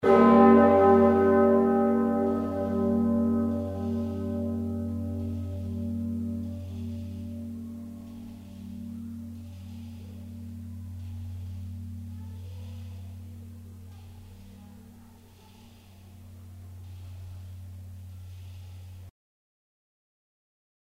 Einige weitere Hörbeispiele zu verschiedenen Glocken: